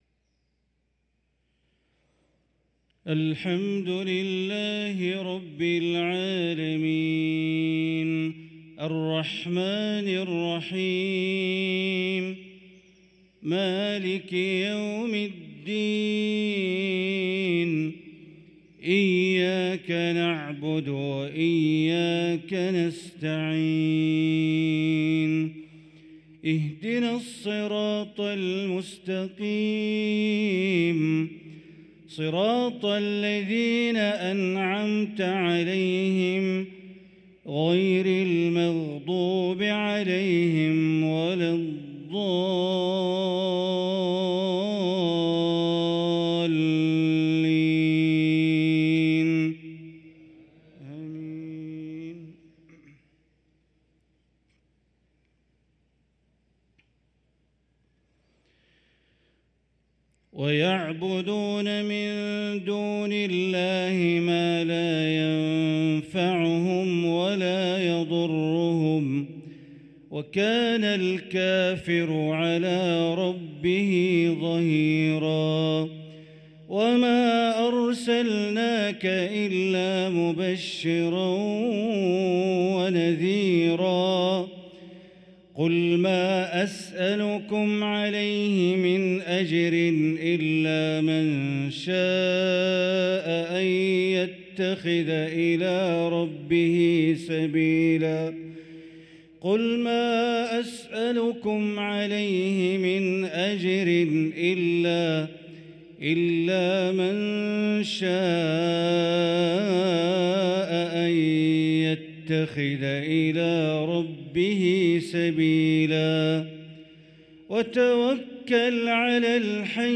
صلاة المغرب للقارئ بندر بليلة 26 صفر 1445 هـ
تِلَاوَات الْحَرَمَيْن .